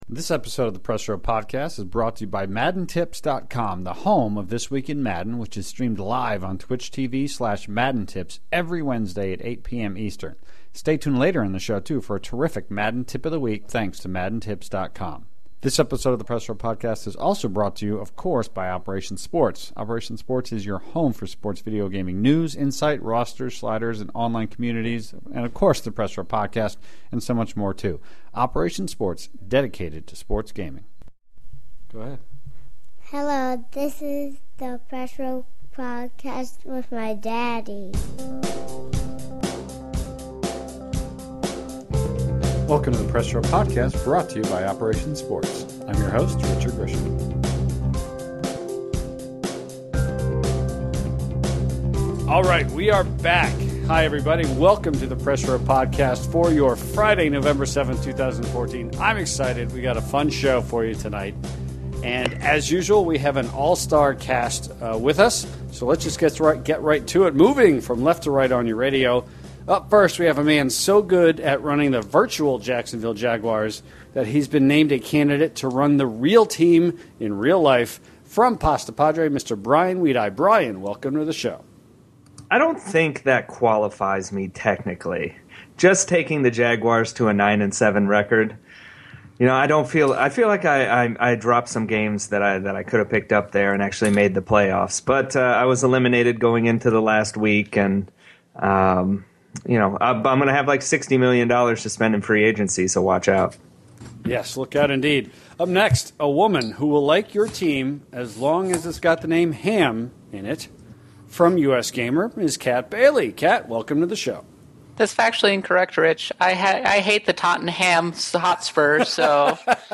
The 108th episode of the Press Row Podcast features a full panel of Press Row regulars revisiting Madden NFL 15, 60 days after its release. We discuss what we enjoy and what nags at us, what makes Madden fun for us this season, and where the overall franchise is today and moving forward. Afterwards, some interesting nuggets came out of EA’s latest financial call, a lot of which directly impacts sports games.